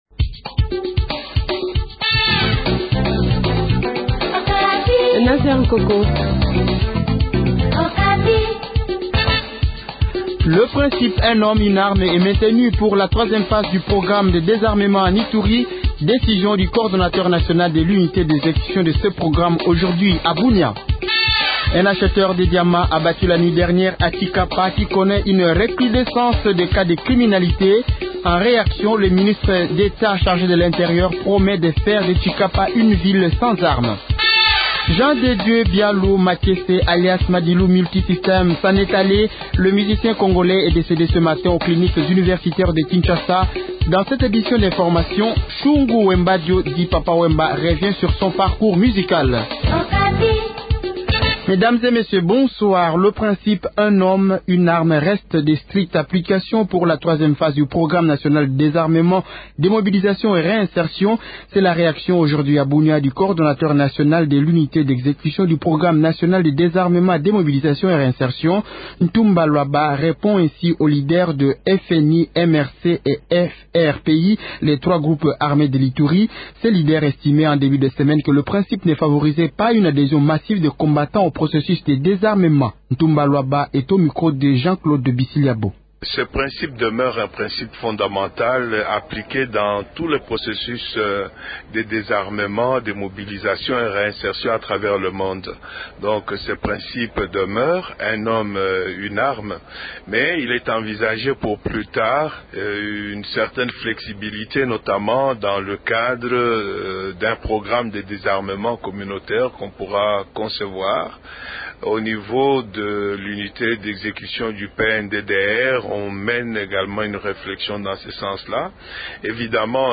Journal Francais Soir